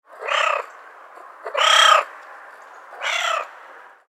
دانلود صدای بچه گربه تازه به راه افتاده از ساعد نیوز با لینک مستقیم و کیفیت بالا
جلوه های صوتی